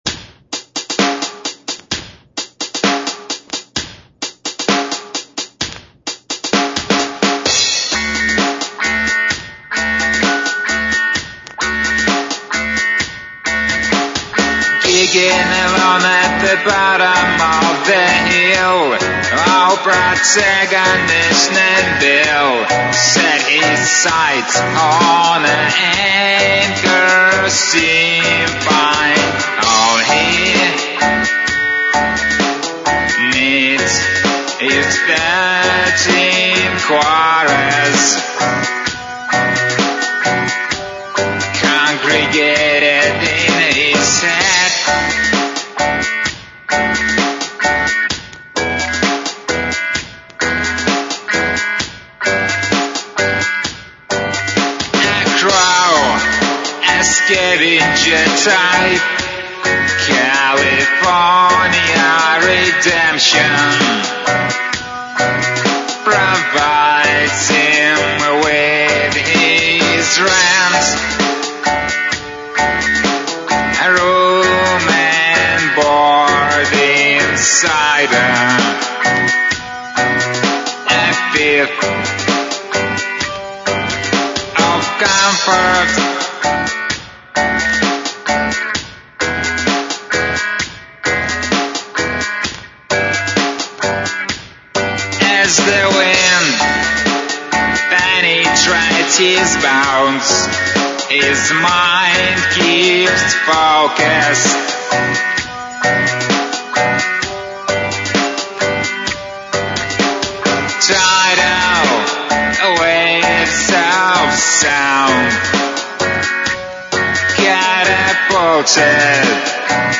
LoFi êà÷åñòâî çàòî âñåãî 800kb, âñ¸ äîìà ñâîèìè ðóêàìè.